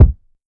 Slap Kick.wav